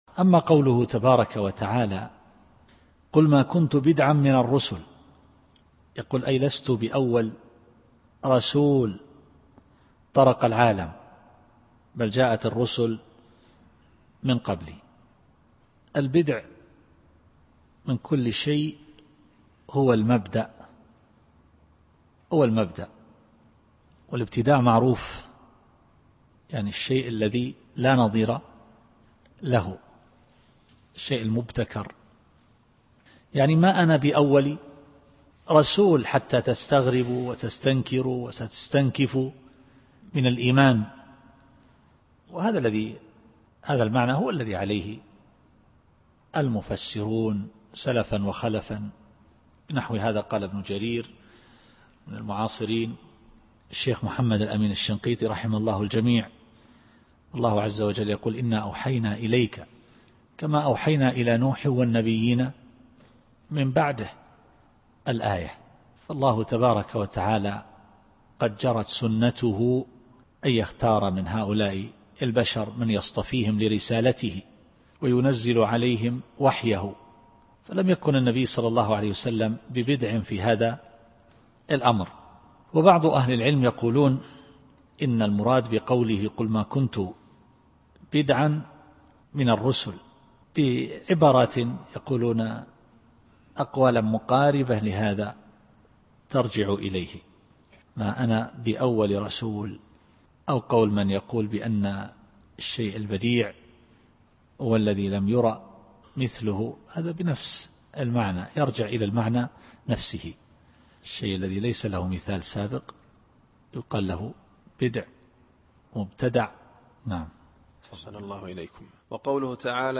التفسير الصوتي [الأحقاف / 9]